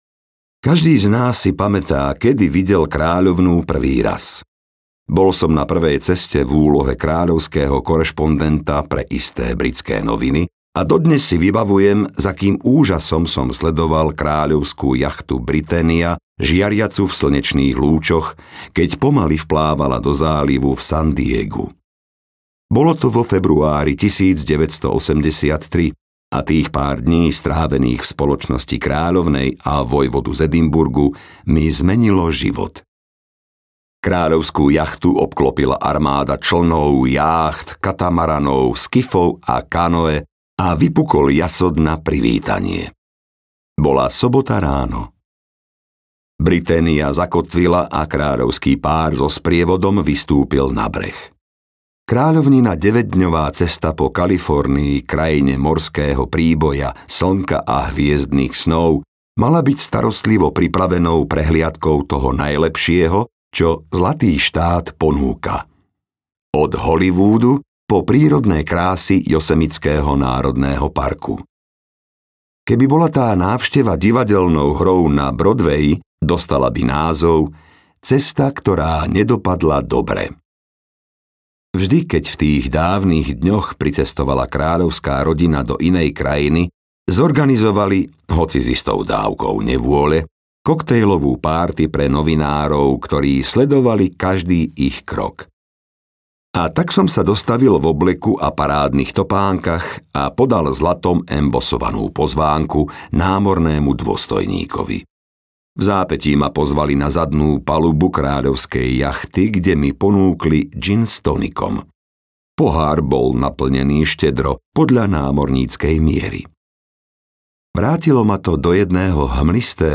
Kráľovná audiokniha
Ukázka z knihy
kralovna-audiokniha